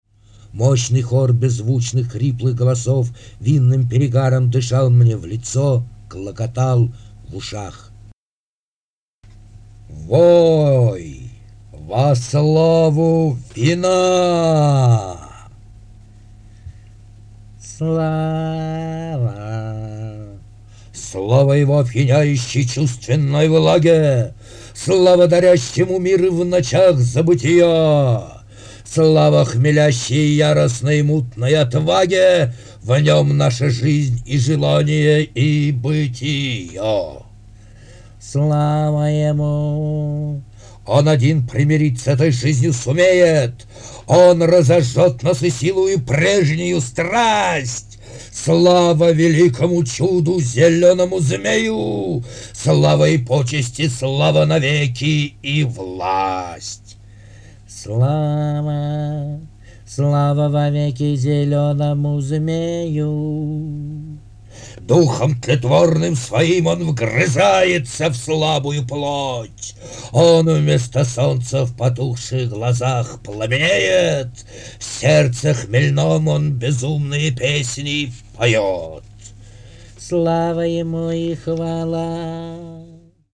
литературная сюита для голоса с воображением
годов ХХ века (на советский кассетный магнитофон Вега-302).